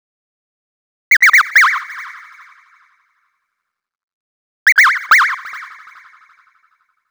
Jfx Tweets.wav